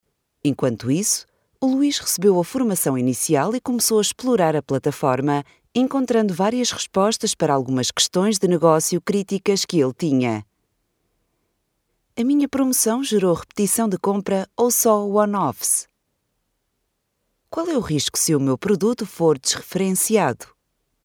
Locutores portugueses de documentales